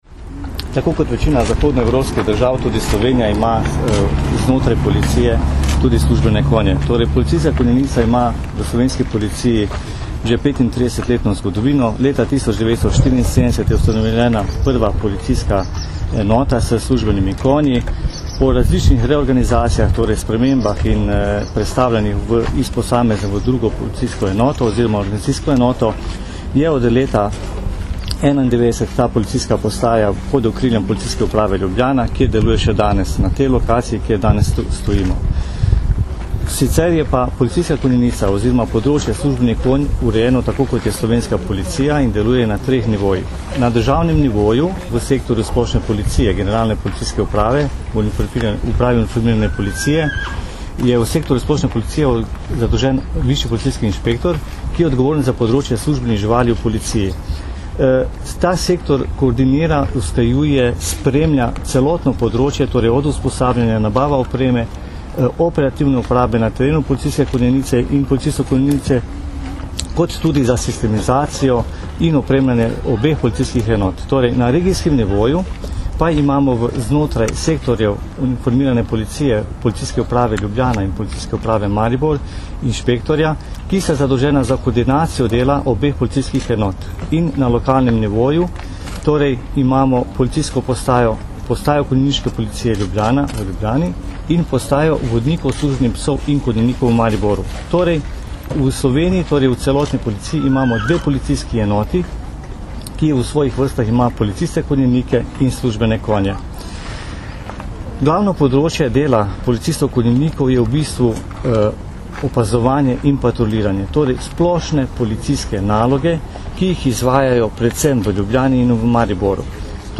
V ljubljanskih Stožicah smo novinarjem danes, 24. avgusta 2009, predstavili Postajo konjeniške policije in specifično delo policistov konjenikov, ki službene konje uporabljajo za patruljiranje, iskanje oseb, pri hujših kršitvah javnega reda in miru ter za druge naloge policije.
Zvočni posnetek izjave